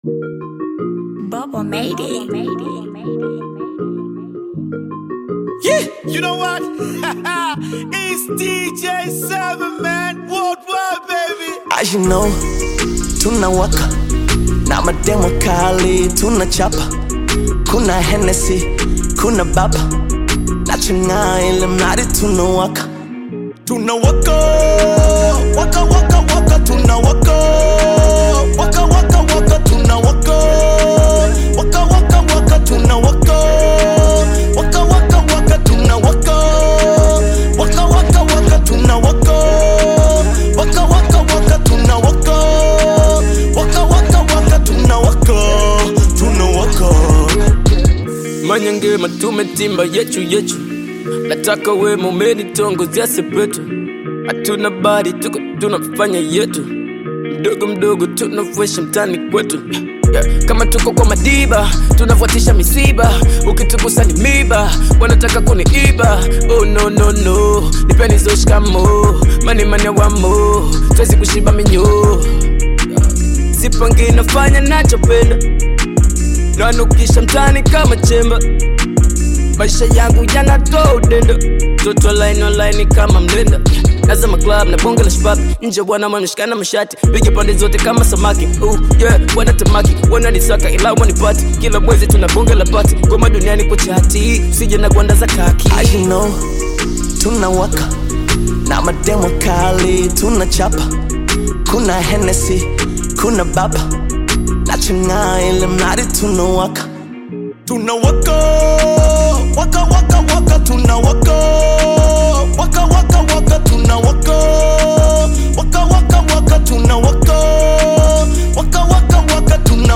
African Music